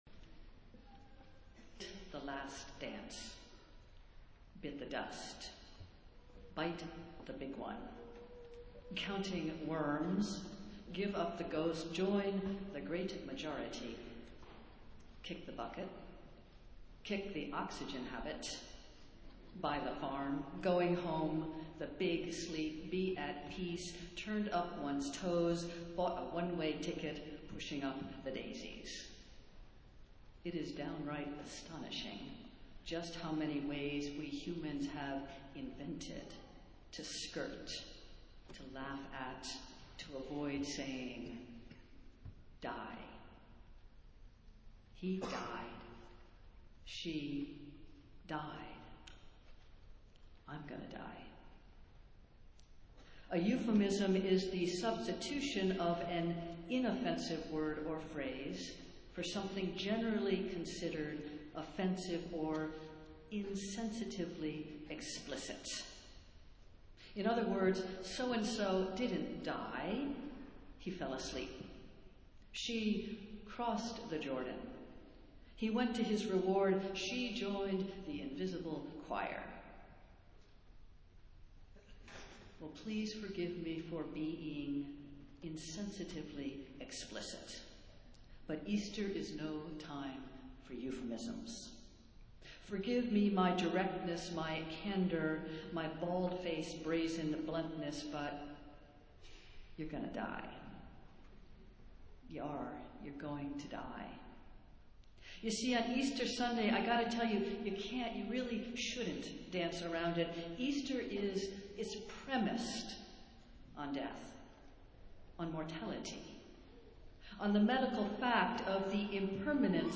Festival Worship - Easter and Marathon Sunday